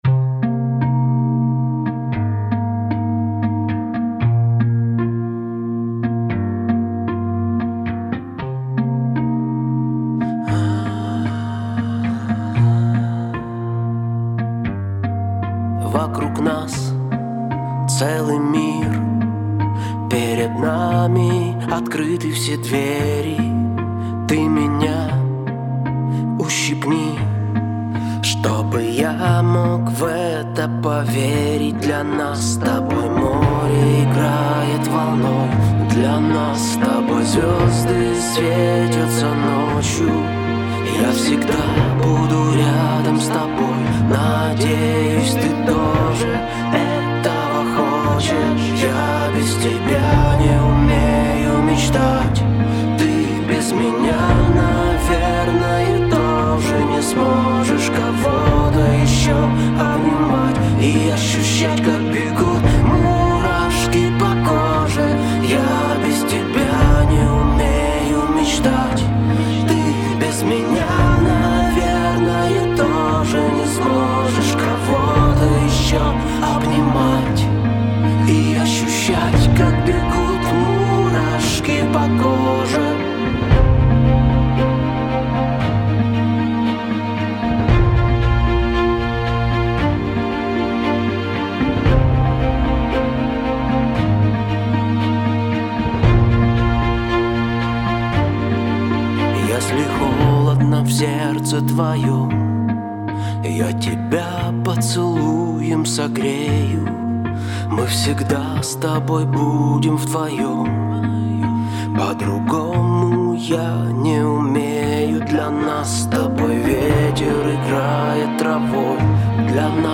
где нежные мелодии переплетаются с громогласными риффами
Вокал, гитара
Бас-гитара
Гитара
Барабаны